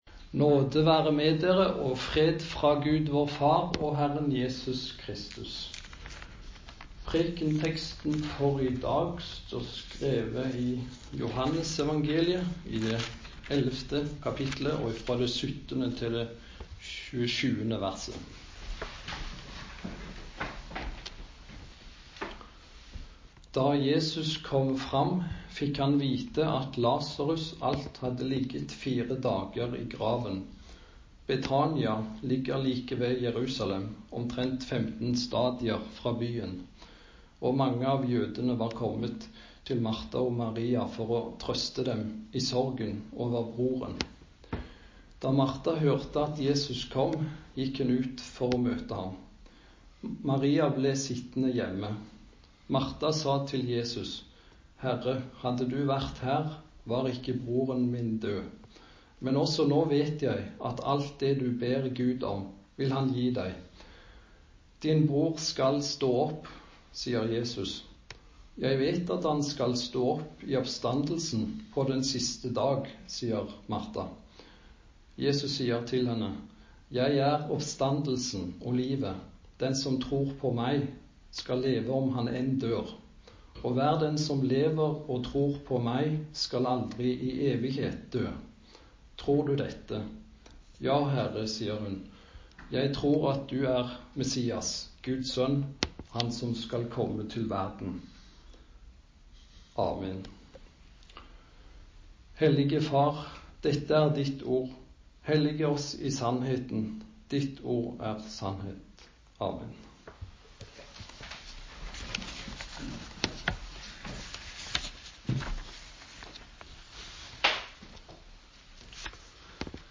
Preken på 16. søndag etter Treenighetsdag